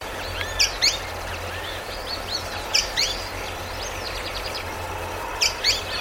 Pijuí Frente Gris (Synallaxis frontalis)
Pareja de pijuís frente gris que se encontraba en un monte de acacias negras (Gleditsia triacanthos), sobre un camino de tierra, en dirección al río o canal 16.
Partido de Saladillo, Provincia de Buenos Aires.
Condición: Silvestre
Certeza: Observada, Vocalización Grabada